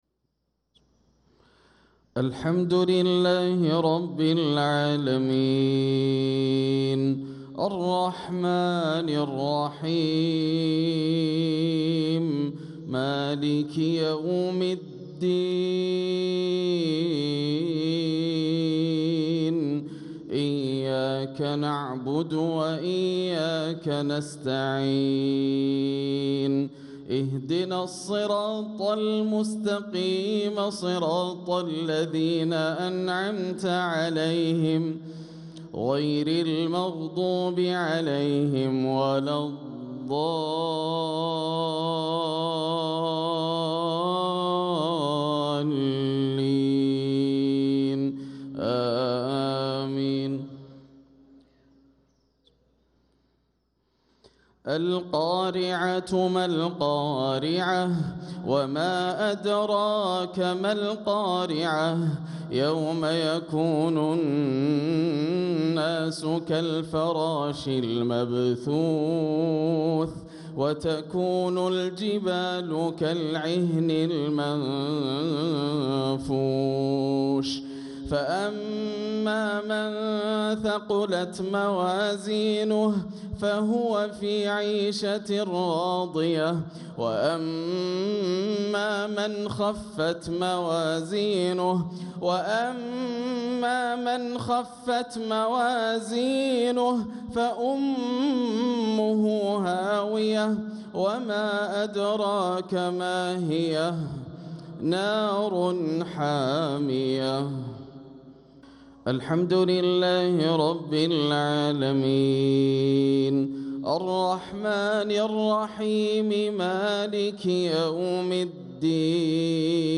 صلاة المغرب للقارئ ياسر الدوسري 29 جمادي الأول 1446 هـ
تِلَاوَات الْحَرَمَيْن .